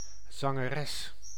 Ääntäminen
France: IPA: /ʃɑ̃.tœʁ//